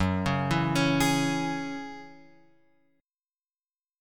F#7sus2 chord {2 4 2 x 2 4} chord